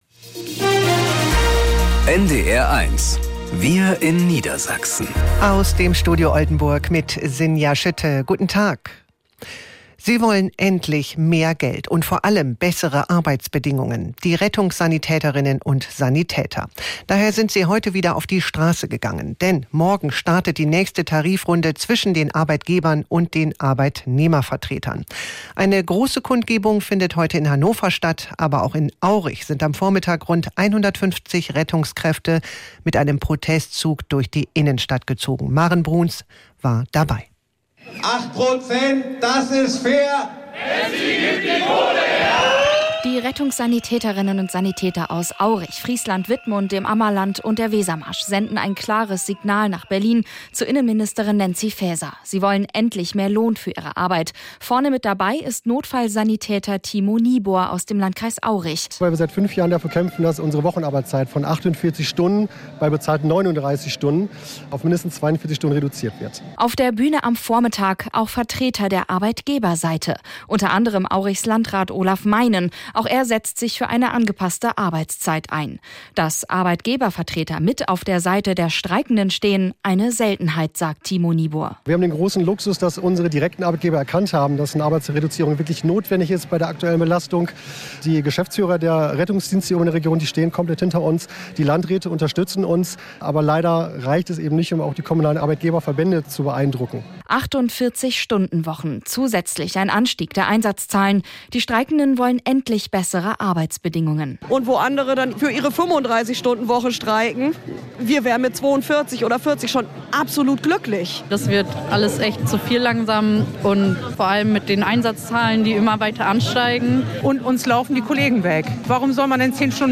… continue reading 4 قسمت # Tägliche Nachrichten # Nachrichten # NDR 1 Niedersachsen